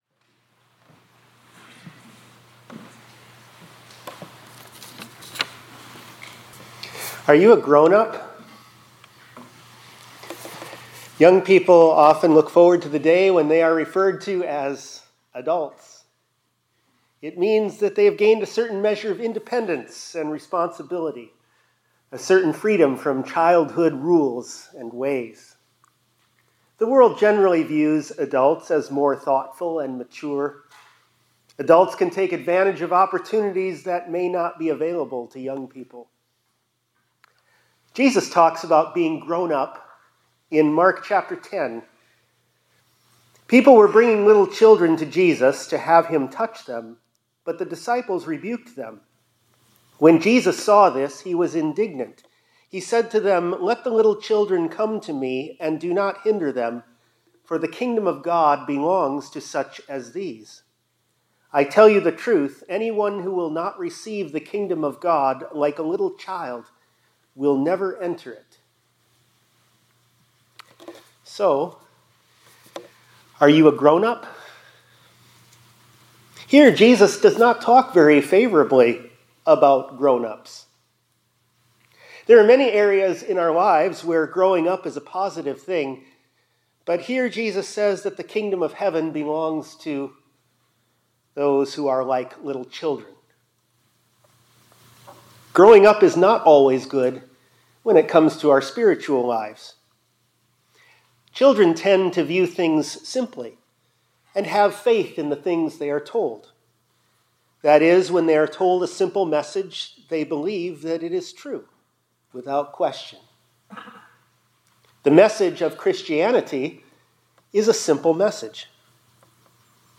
Tour Choir Selections